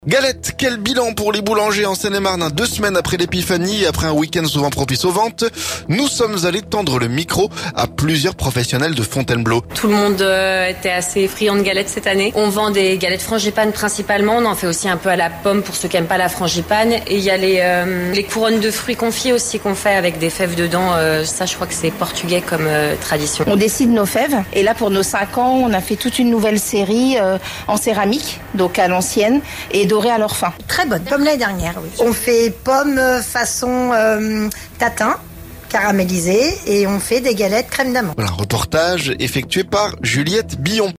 Galette : Quel bilan pour les boulangers en Seine-et-Marne? Deux semaines après l'épiphanie, et après un week-end souvent propice aux ventes, nous sommes allés tendre le micro à plusieurs professionnels de Fontainebleau.